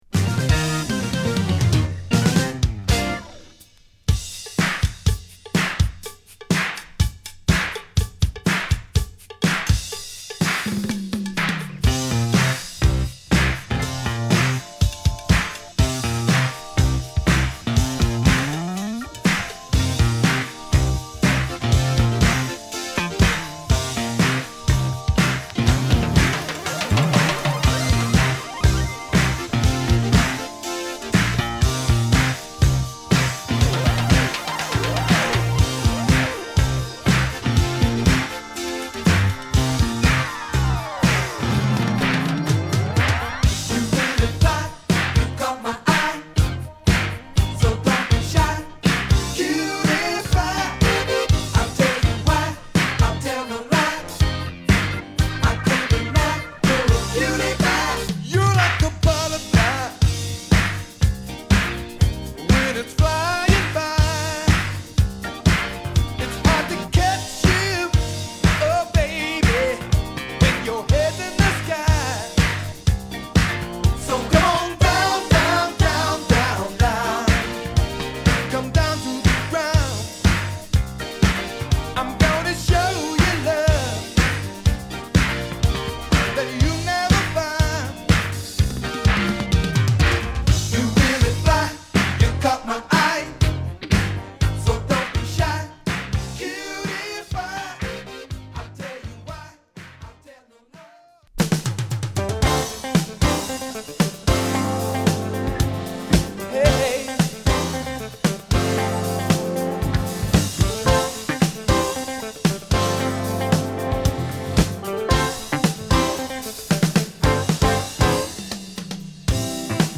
アーバンなミディアムソウルダンサー